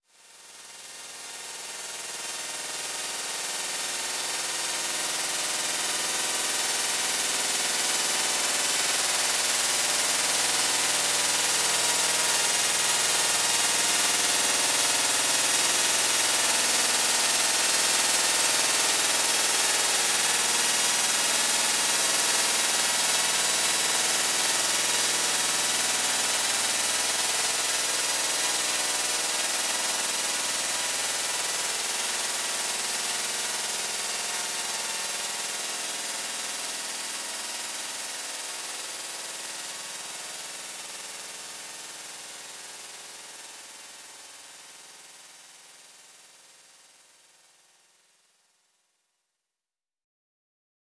electroacoustic music